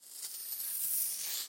Звук мела чертящего линию или круг